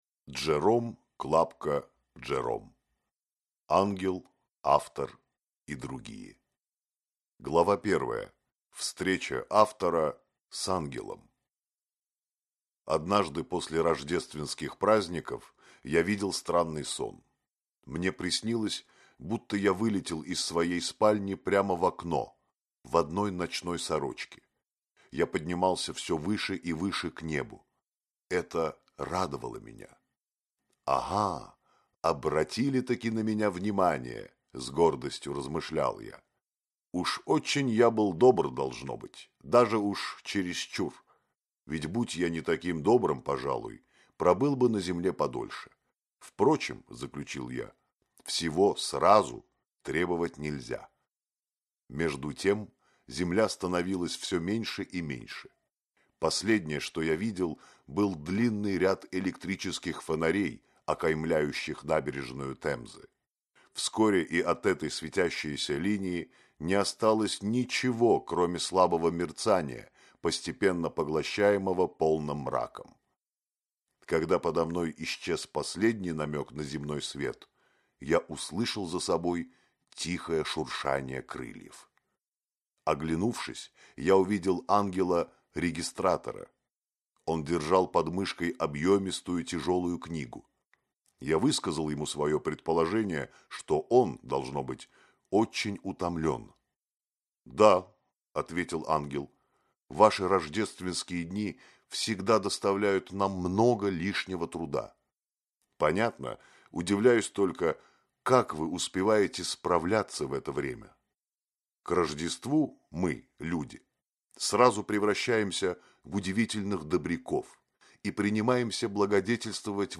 Аудиокнига Ангел автор и другие | Библиотека аудиокниг